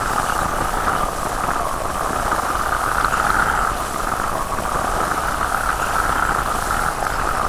KHLOGeyser01.wav